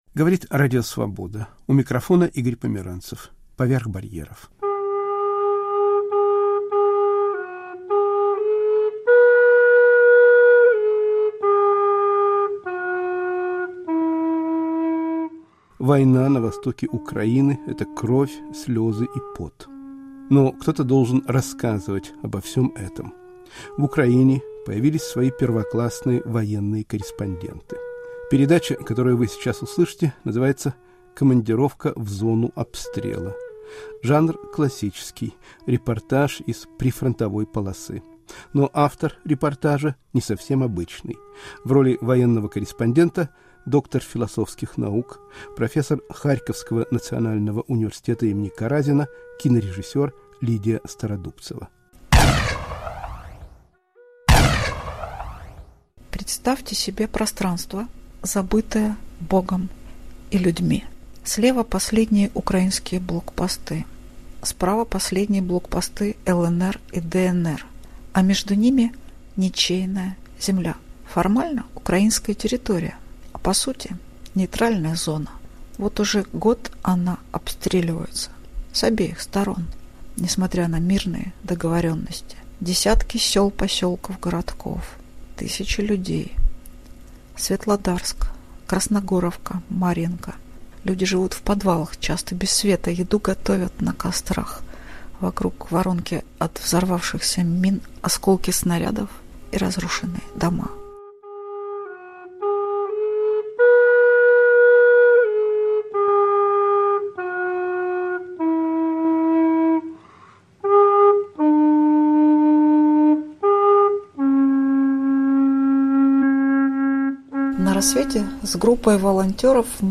Репортаж из прифронтовой полосы